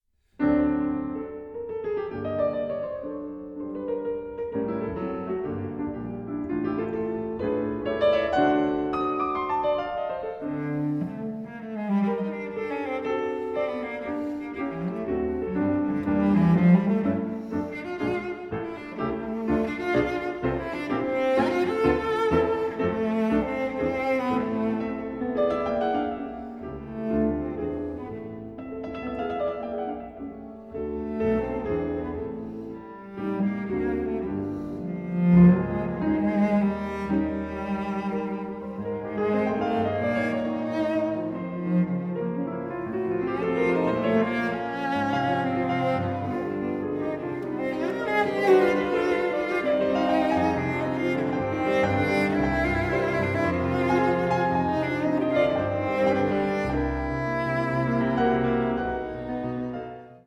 cello
piano
Allegro
Recorded June 2014 at Teldex Studio Berlin, Germany